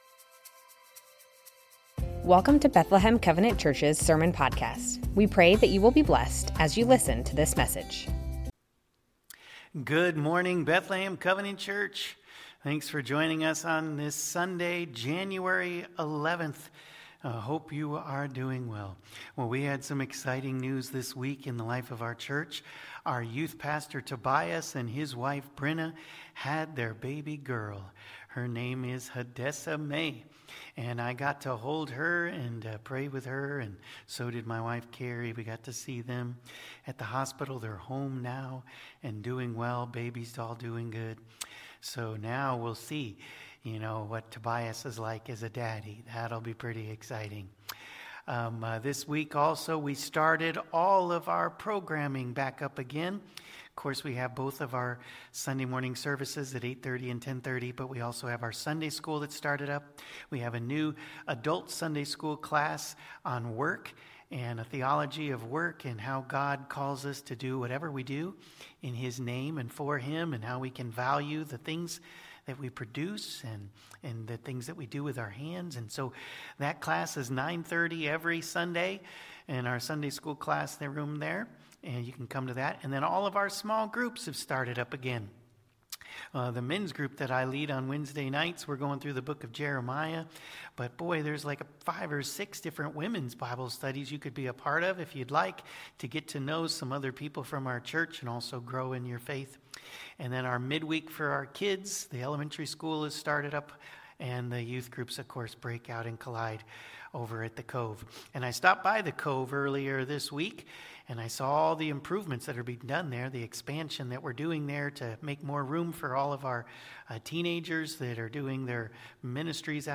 Bethlehem Covenant Church Sermons The 10 Commandments - No Other Gods Before Me Jan 11 2026 | 00:38:21 1x 00:00 / 00:38:21 Subscribe Share Spotify RSS Feed Share Link Embed